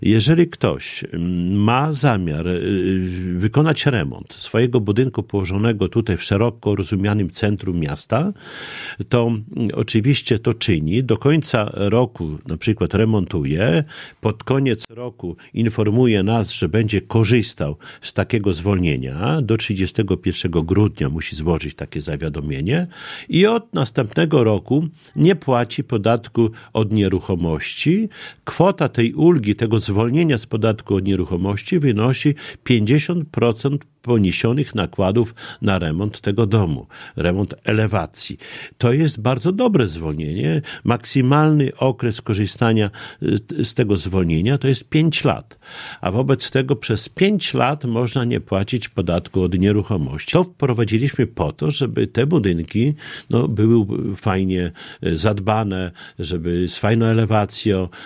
Z podatku od nieruchomości zostają zwolnieni właściciele budynków w centrum, którzy zdecydują się na odnowienie elewacji. Jak mówi Czesław Renkiewicz, prezydent Suwałk, w zamian za renowację właściciele mogą liczyć na zwolnienie z podatku do 5 lat.